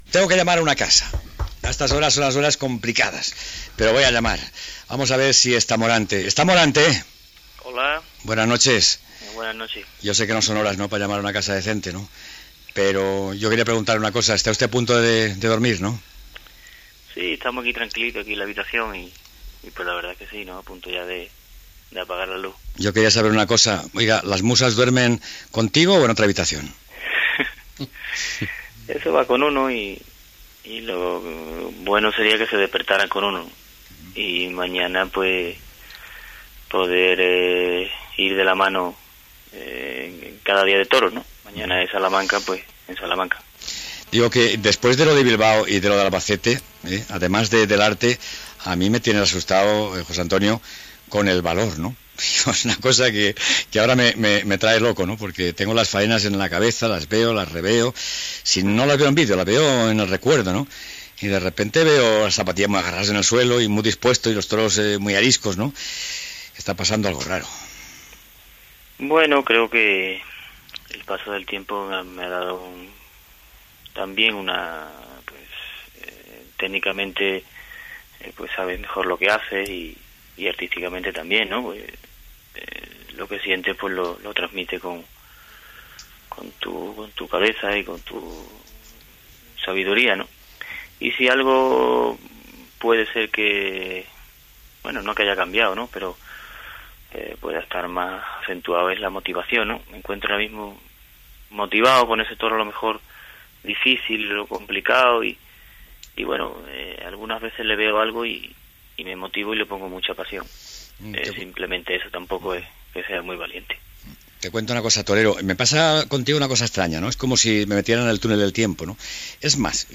Entrevista al torero Morante de la Puebla (José Antonio Morante), que demà toreja a Salamanca i en uns dies després matarà sol una "corrida" de sis braus a Saragossa
Informatiu